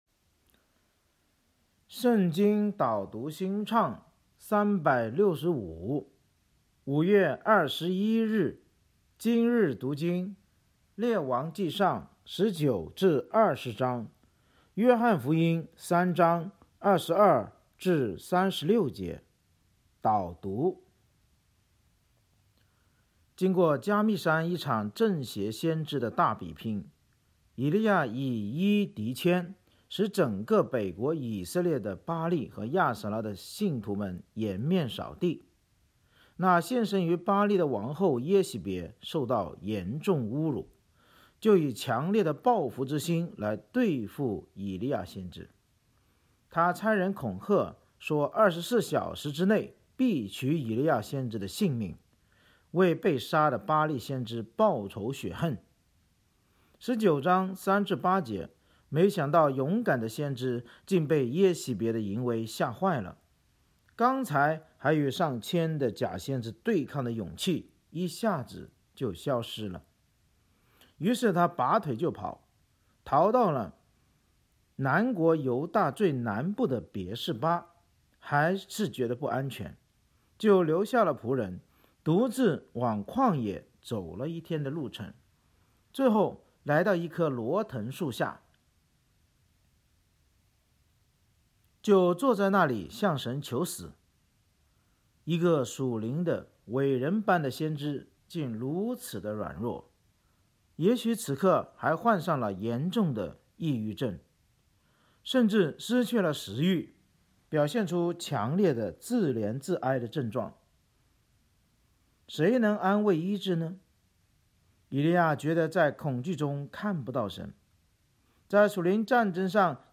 【经文朗读】